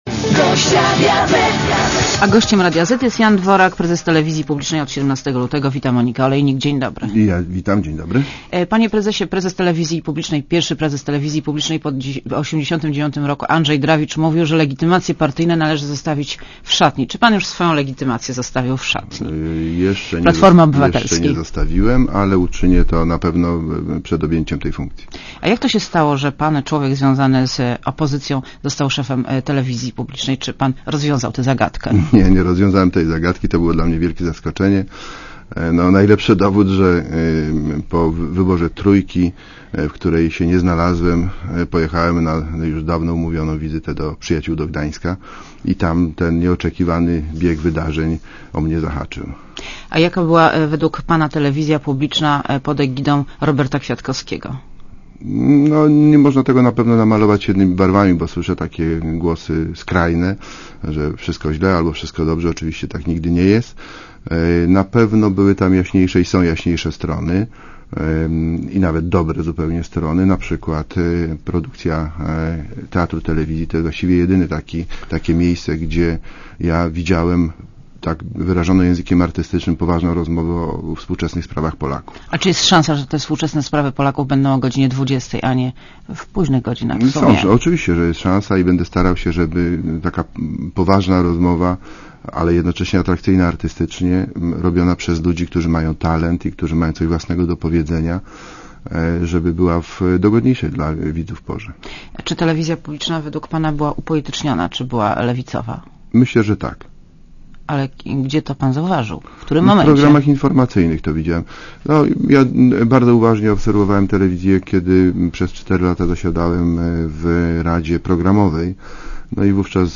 Gościem Radia Zet jest Jan Dworak, prezes telewizji publicznej, od 17 lutego. Wita Monika Olejnik.